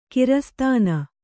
♪ kirastāna